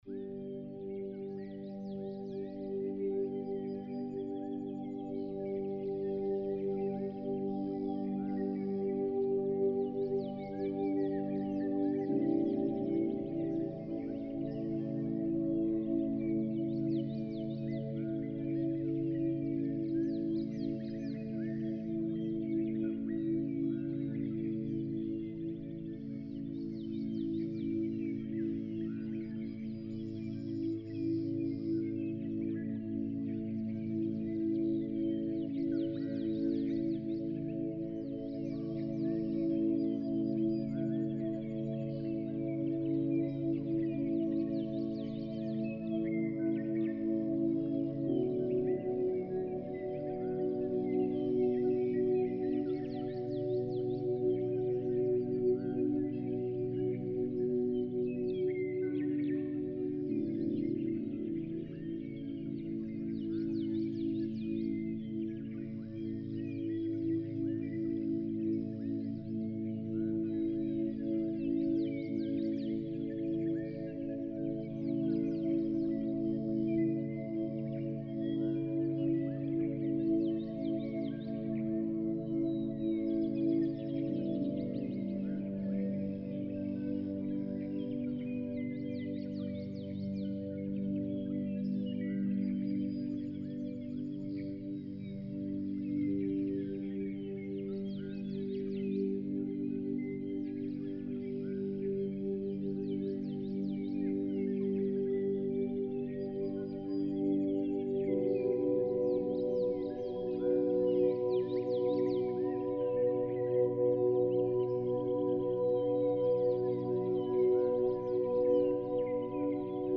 15hz - Beta Beats for Attention, Memory & Flow ~ Binaural Beats Meditation for Sleep Podcast
Mindfulness and sound healing — woven into every frequency.